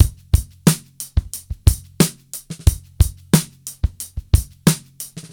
• 90 Bpm Drum Loop Sample D Key.wav
Free drum loop sample - kick tuned to the D note.
90-bpm-drum-loop-sample-d-key-ebQ.wav